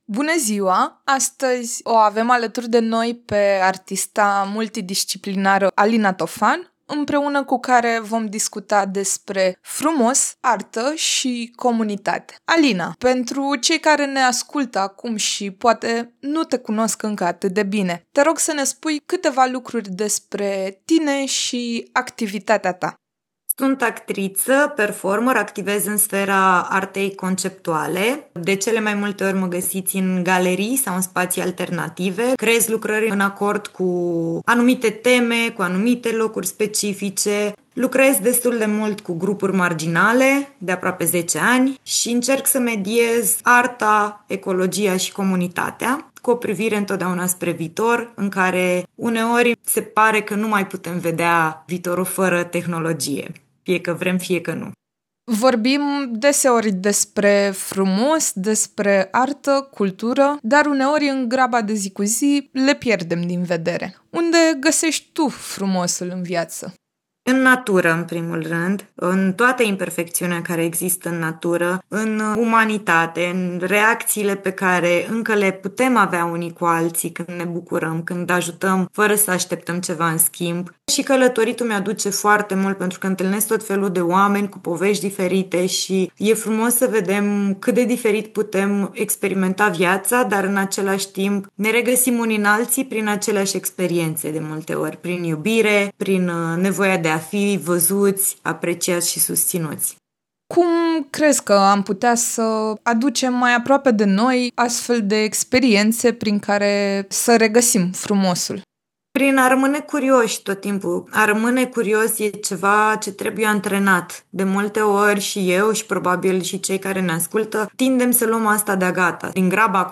Vă invităm să ascultați o conversație despre frumos și despre lucrurile care ne aduc mai aproape unii de ceilalți.